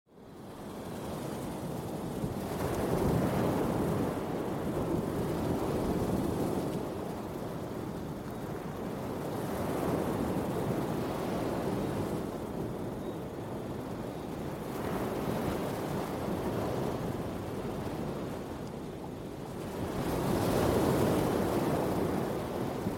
Здесь вы найдете успокаивающие композиции из шума прибоя, пения цикад и легкого ветра — идеальный фон для отдыха, работы или сна.
Звук океанских волн на закате солнца записанный на Ямайке